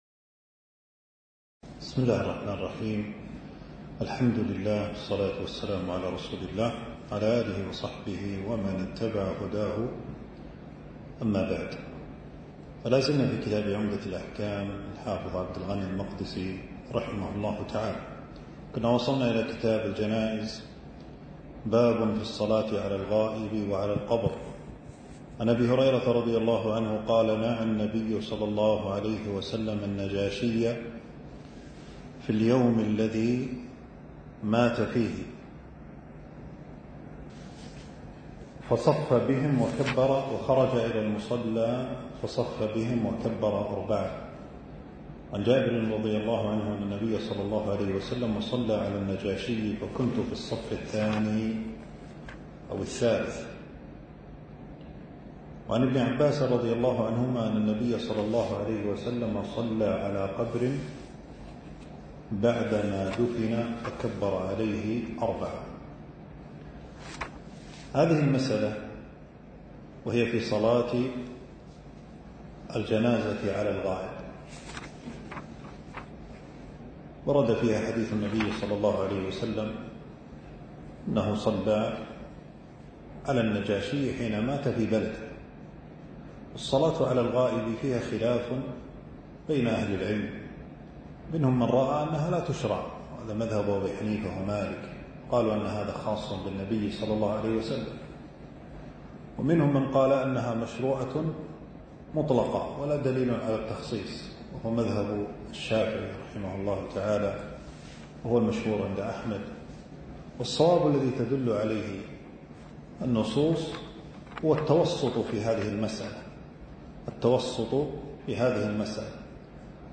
كتاب الجنائز (الدرس السابع) ألقاه